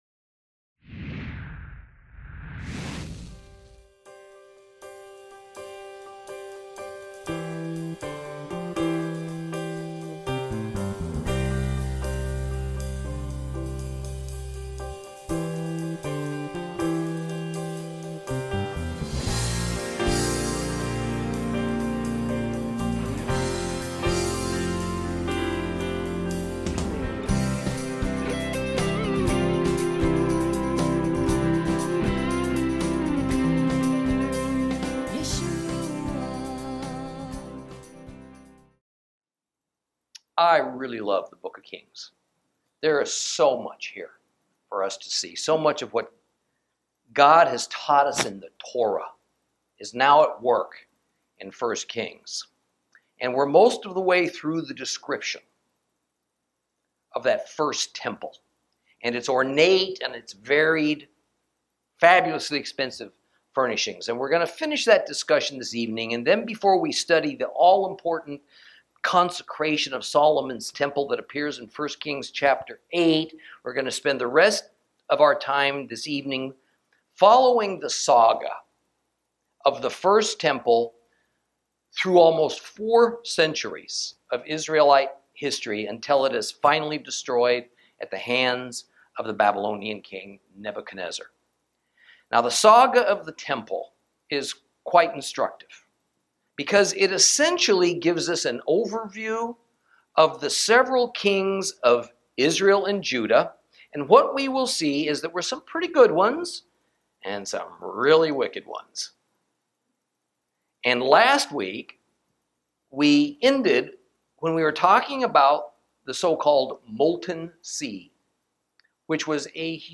Lesson 12 Ch7 - Torah Class